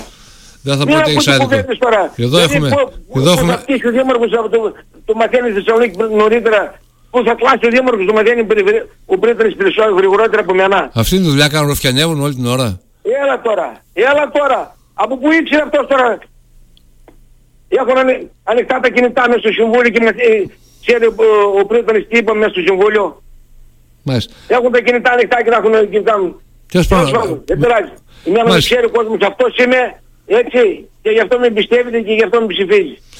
Σε μια απίστευτη καταγγελία προχώρησε ο Κώστας Μαράβας που μίλησε στην εκπομπή «Χαμηλές Πτήσεις» στο Ράδιο Ζυγός για «ρουφιάνους» μέσα στο Δημοτικό Συμβούλιο: «Πριν να φτύσει ο δήμαρχος το μαθαίνει η Θεσσαλονίκη νωρίτερα, πως θα κλάσει ο δήμαρχος το μαθαίνει ο πρύτανης γρηγορότερα από μένα; Εχουν ανοιχτά τα κινητά μέσα στο συμβούλιο και ξέρει ο πρύτανης τι είπαμε εκεί μεσα;» τόνισε χαρακτηριστικά: